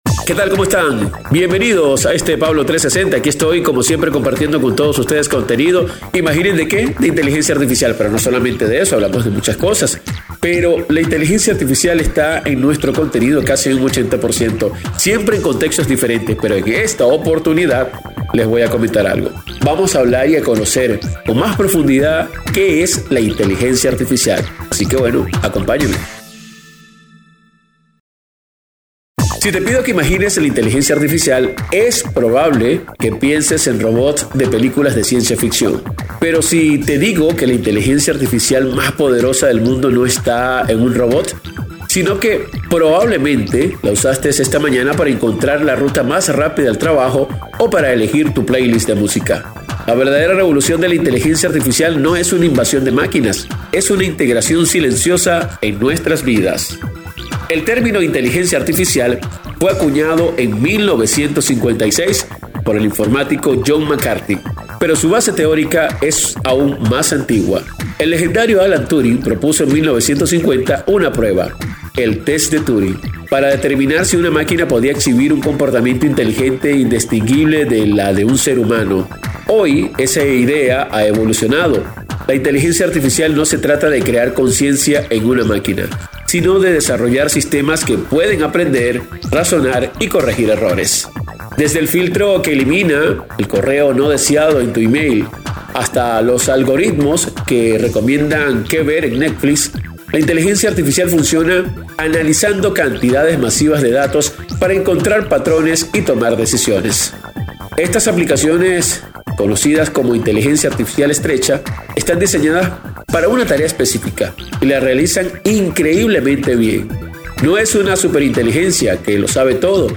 microprograma diario